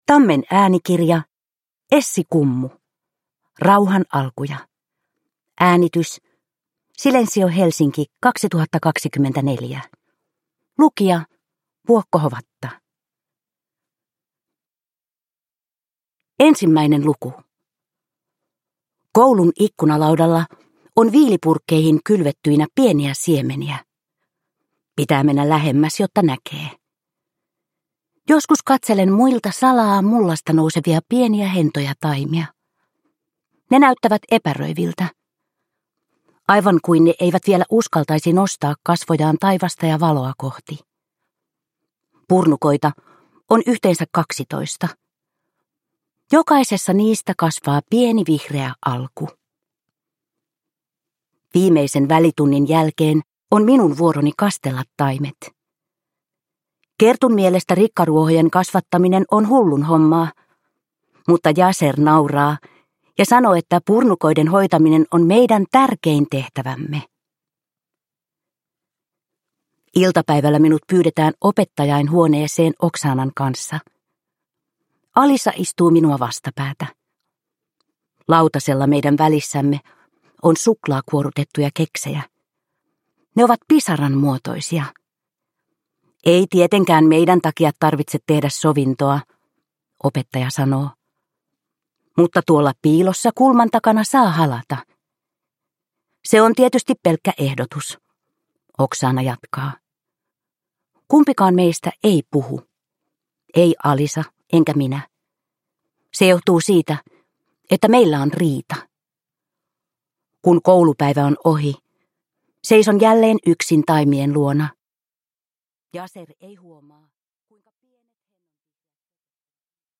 Rauhan alkuja – Ljudbok